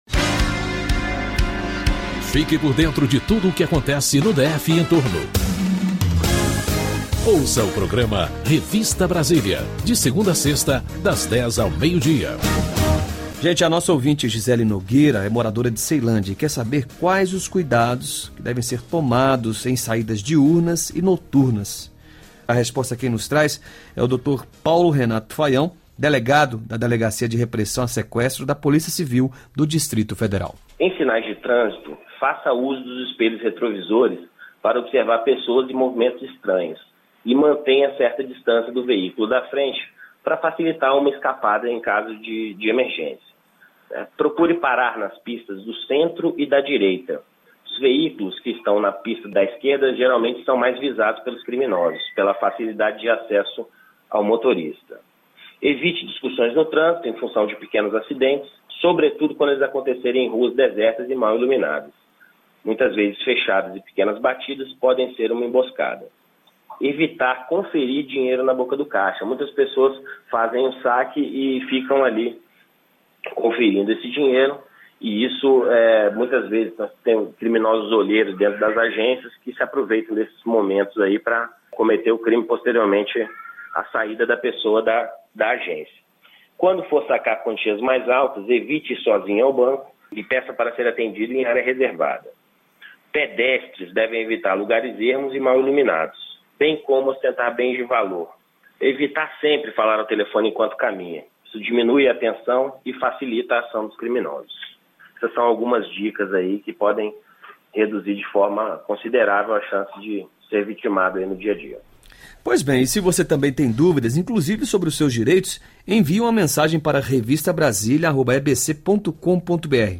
O programa Revista Brasília está no ar de segunda a sexta, das 10h às 12h, na Rádio Nacional de Brasília, AM 980.